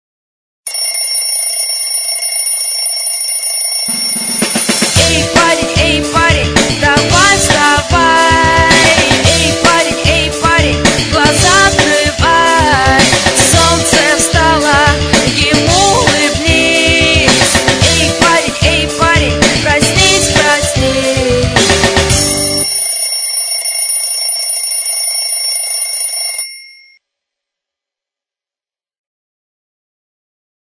песня Размер